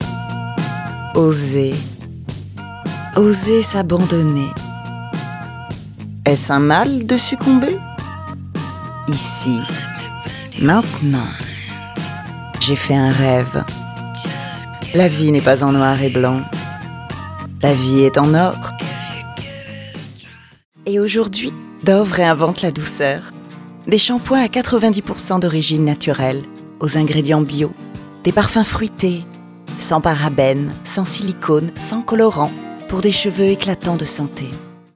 Bande démo voice over
30 - 55 ans - Mezzo-soprano